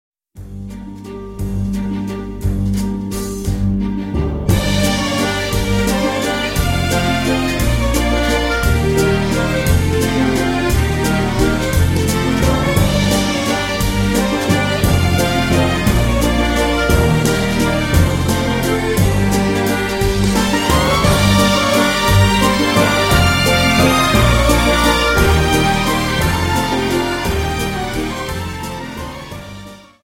Dance: Viennese Waltz Song